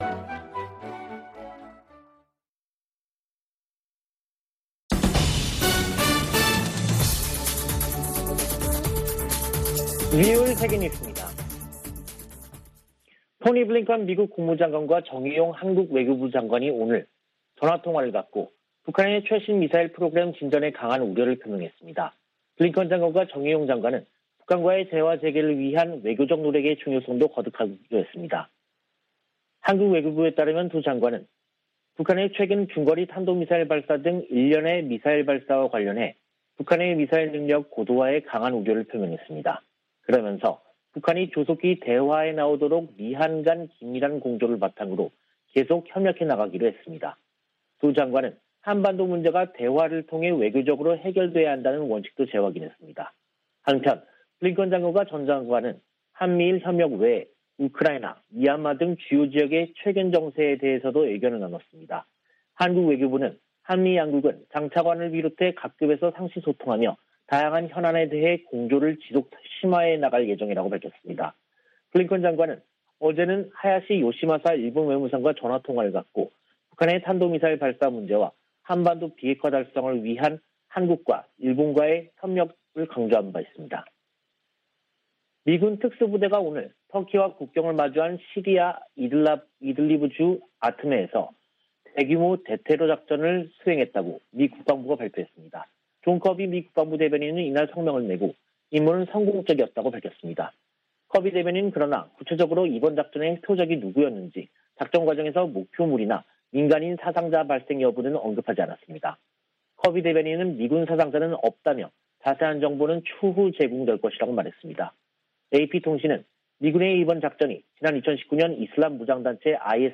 VOA 한국어 간판 뉴스 프로그램 '뉴스 투데이', 2022년 2월 3일 3부 방송입니다. 미한 연합훈련 연기 가능성과 관련해 한반도 준비태세를 진지하게 받아들인다고 미 국방부가 밝혔습니다. 미한 외교장관들은 북한의 미사일 능력 고도화에 우려하며, 한반도 문제는 외교적으로 해결해야 한다는 원칙을 재확인했습니다. 오는 6일 개최하는 북한 최고인민회의에 김정은 국무위원장이 등장할지, 어떤 대외 메시지를 발신할지 관심이 모이고 있습니다.